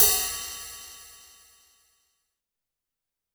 • Big Room Ride Cymbal Drum Sample E Key 05.wav
Royality free ride sample tuned to the E note. Loudest frequency: 9973Hz
big-room-ride-cymbal-drum-sample-e-key-05-cGS.wav